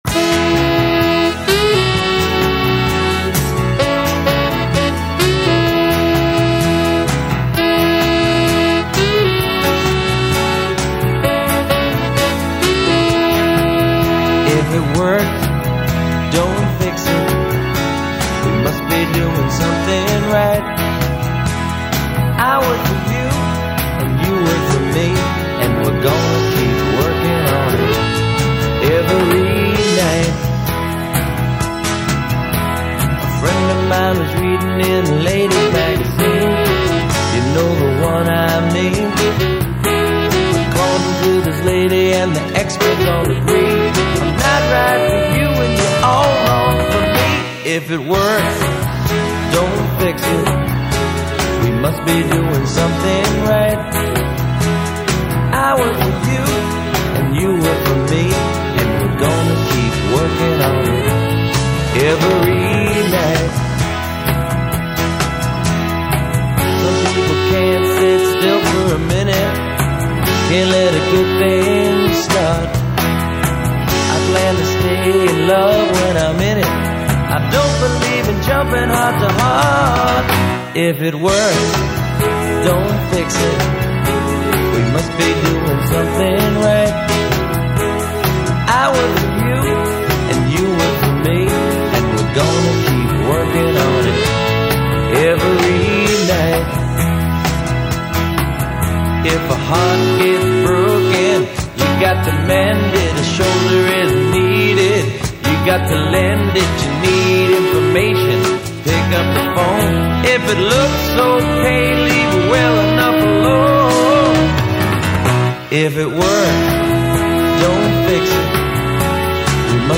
guitar, bass, vocals
drums
keyboards
saxophone, flute